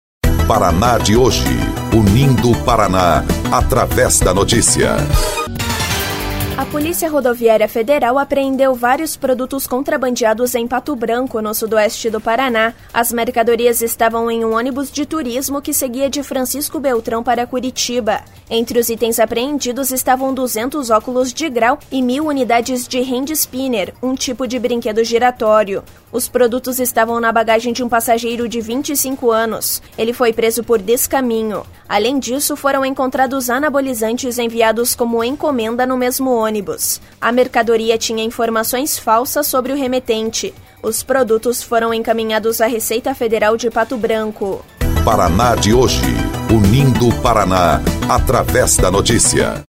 BOLETIM – PRF apreende produtos contrabandeados e anabolizantes em ônibus